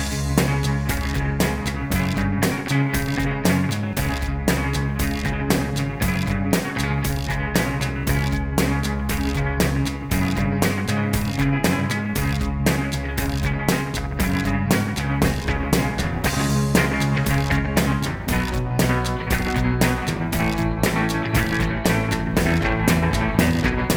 Minus Solo Guitar For Guitarists 4:39 Buy £1.50